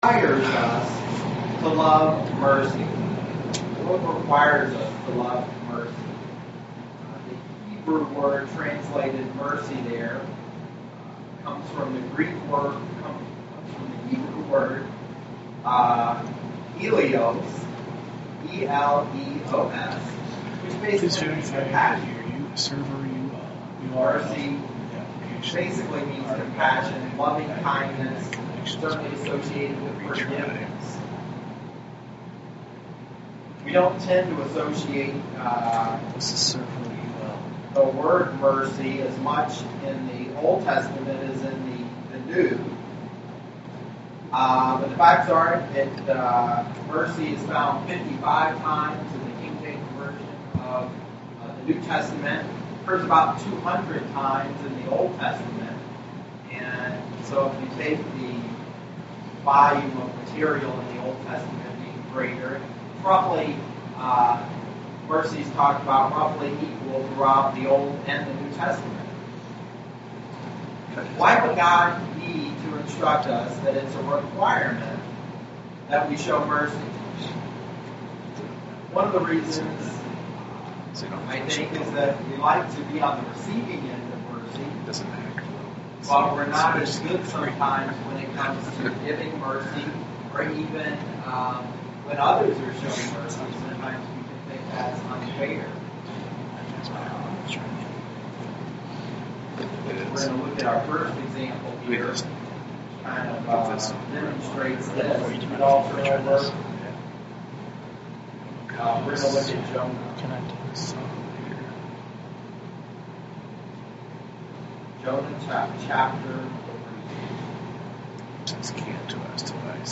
Given in Lansing, MI
UCG Sermon